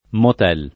motel